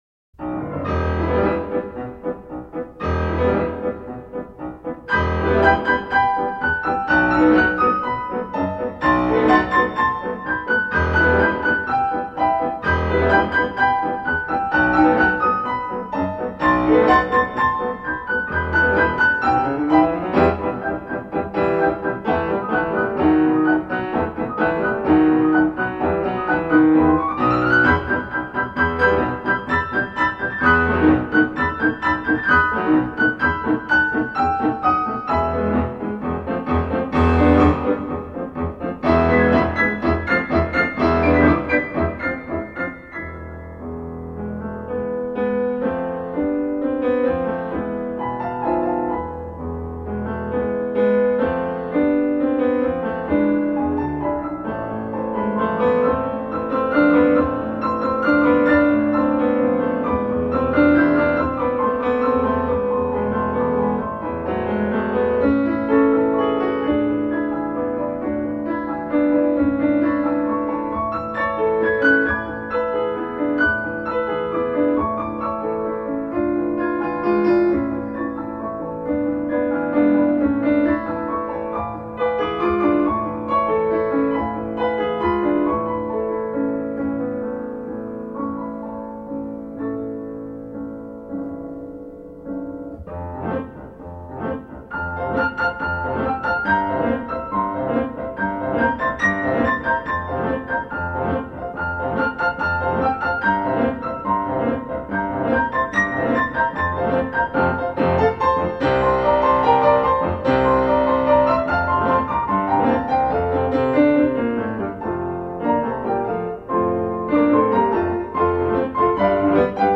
(performance)